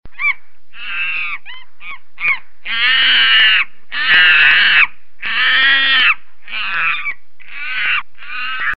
• Качество: высокое
Макака громко кричит